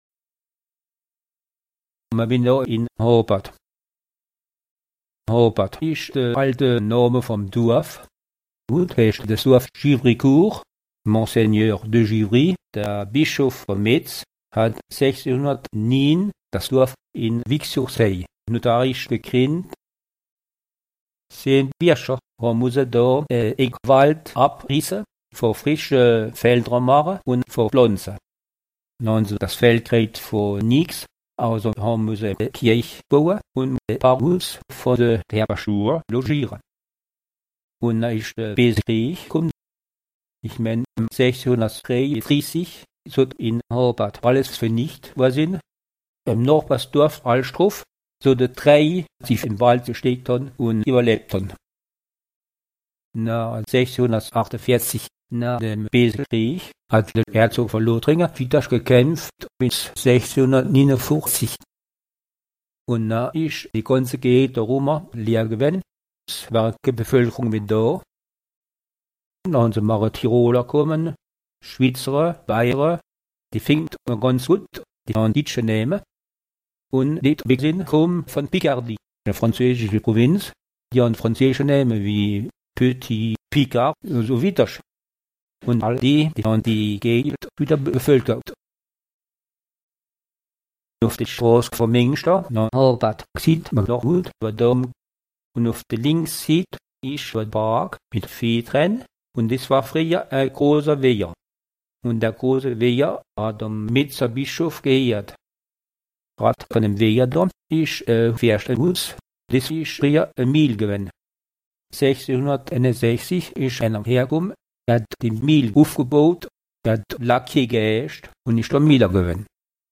Contes et chansons en dialecte roman ou allemand, enregistrés dans les communes d’Assenoncourt, Loudrefing, Lohr, Munster, Givrycourt, Vibersviller, Albestroff et de Blâmont.